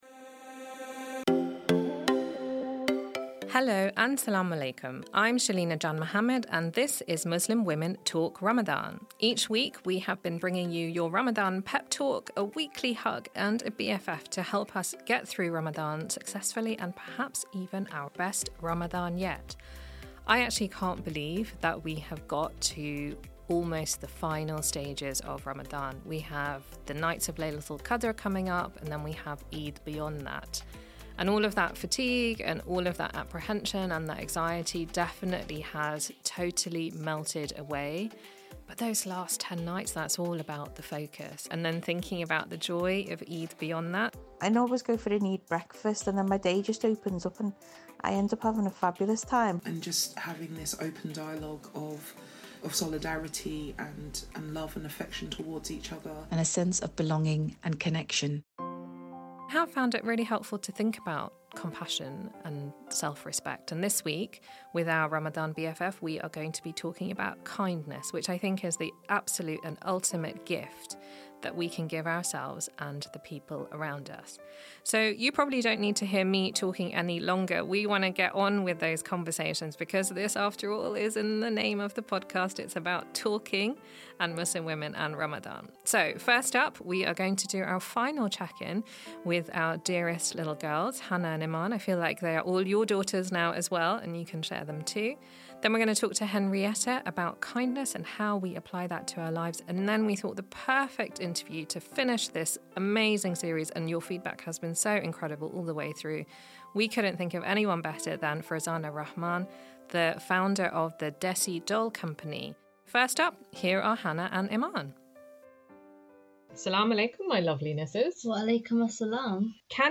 talks to us in our special guest interview